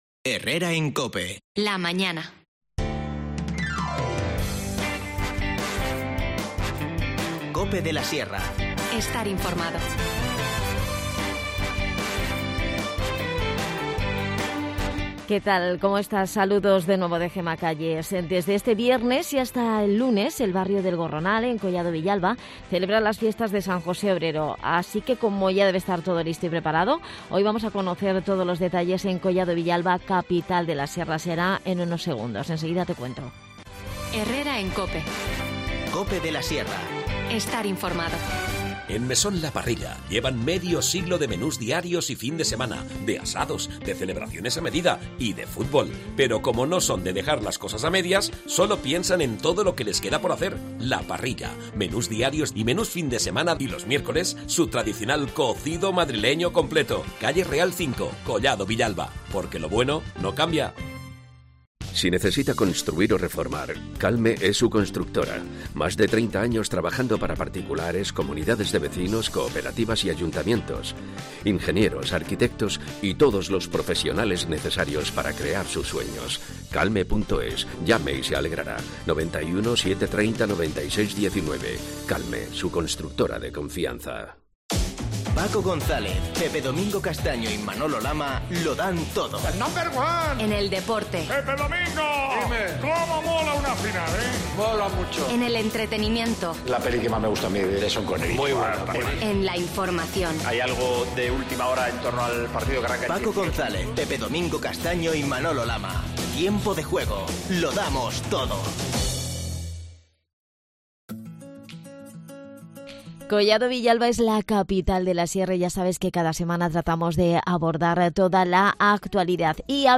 Nos habla de la programación Carlos Sanz, concejal de Servicios a la Cuidad, Obras y Festejos.